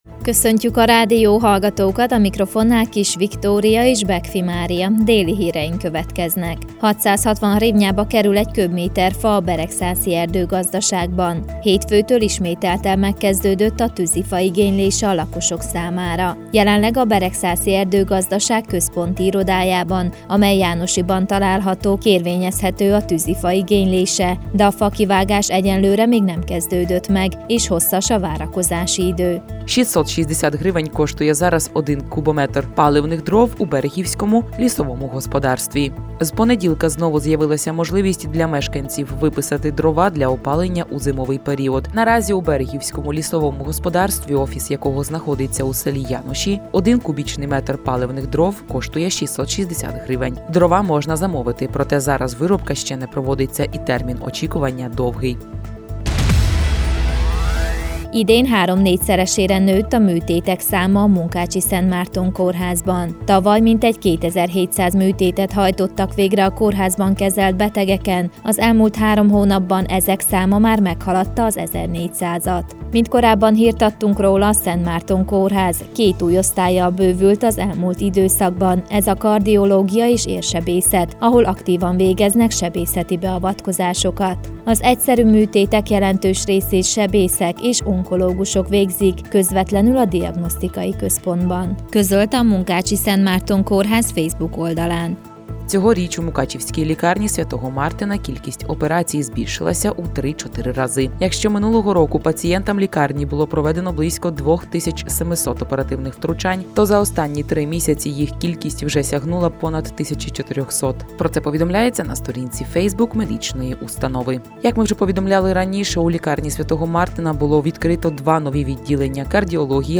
2022. július 7., csütörtök, déli hírek.